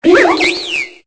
Cri de Munna dans Pokémon Épée et Bouclier.